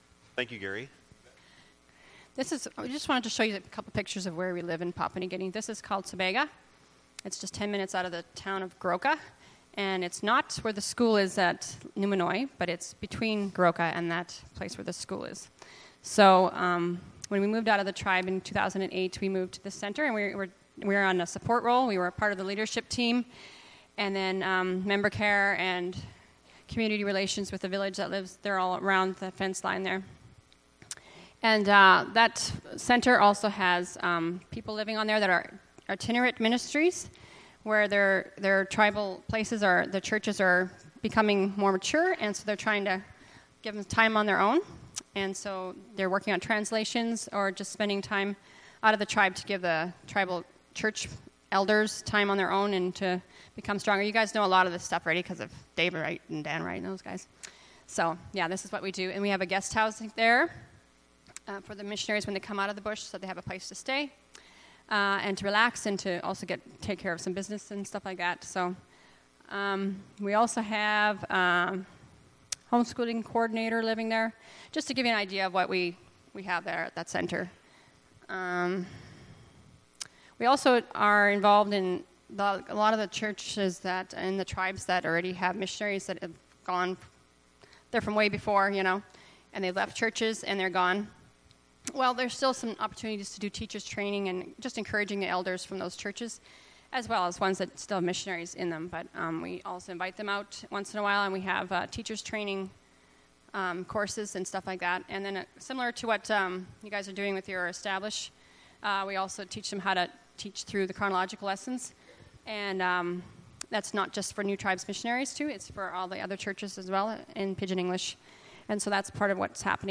Bible Text: John 4 | Preacher: Guest Preacher
Service Type: Sunday Morning